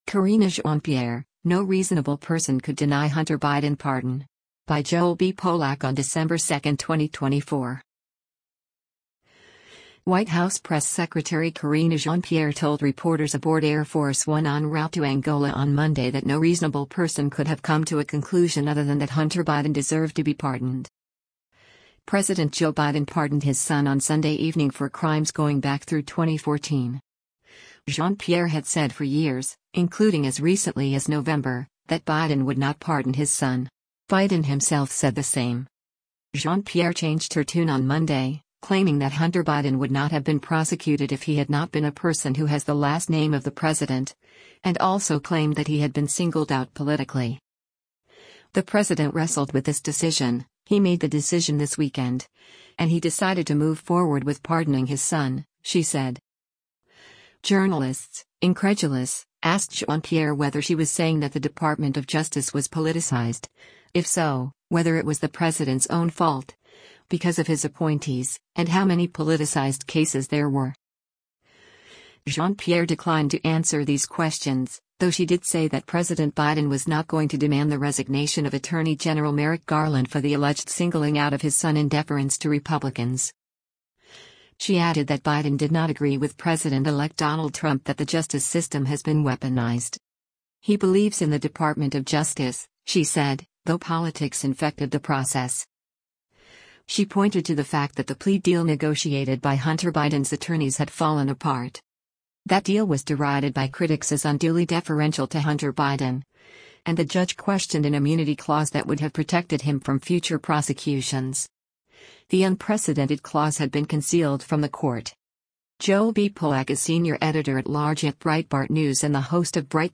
White House Press Secretary Karine Jean-Pierre told reporters aboard Air Force One en route to Angola on Monday that “no reasonable person” could have come to a conclusion other than that Hunter Biden deserved to be pardoned.